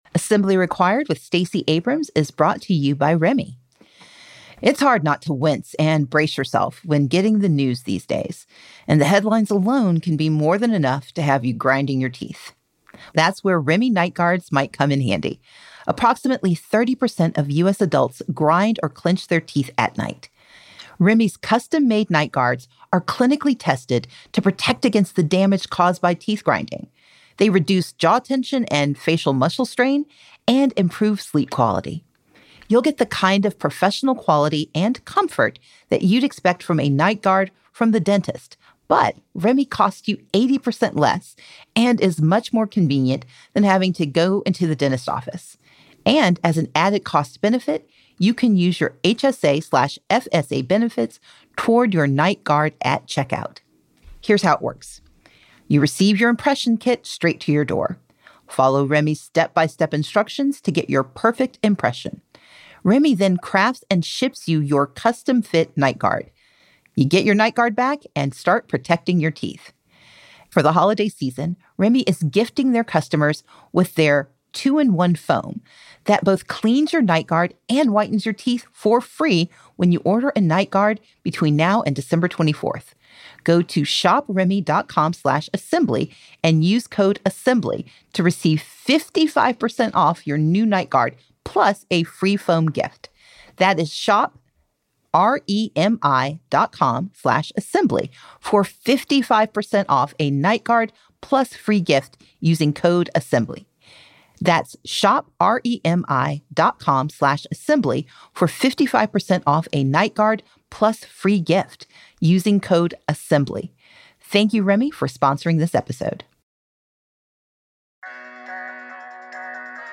This week, Stacey is joined by former Special Assistant to the President for Technology and Competition Policy, law professor, and author of The Age of Extraction, Tim Wu to break down how these dominant platforms manipulate attention, extract wealth, and deepen inequality.